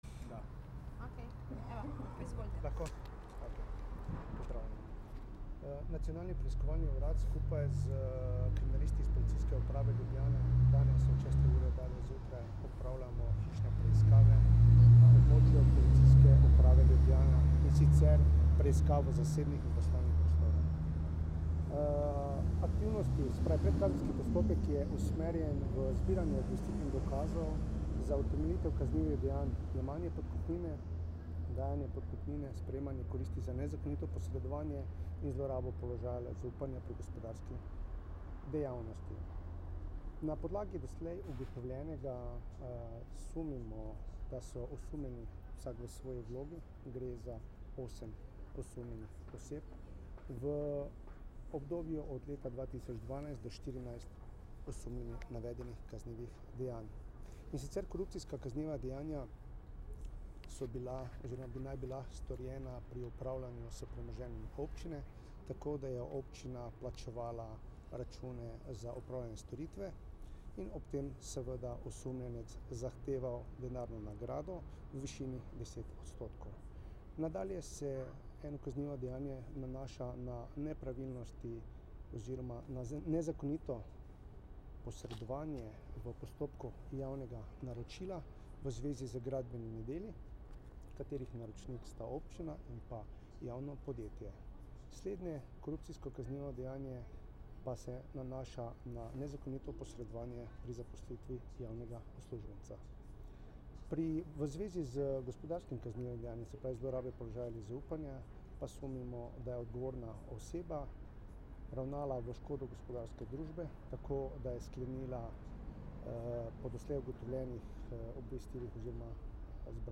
Zvočni posnetek izjave Darka Majheniča, direktorja Nacionalnega preiskovalnega urada (mp3)